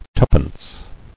[two·pence]